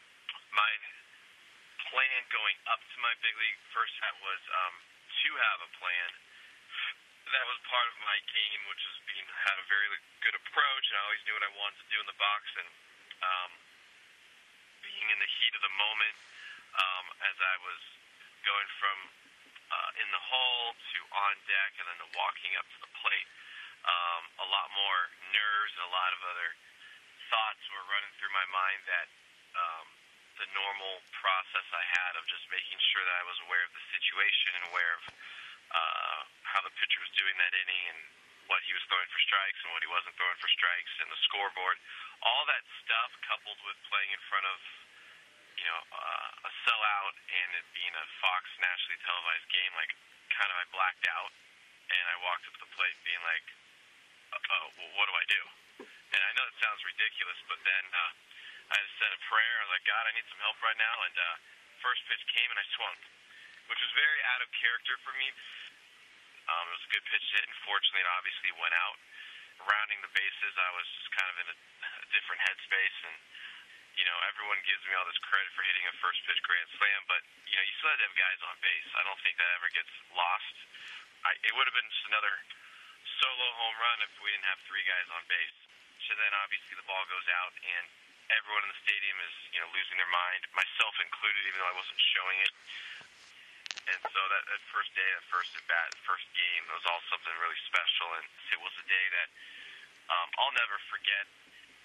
1977 LISTEN: Al Woods on his first career home run (Interview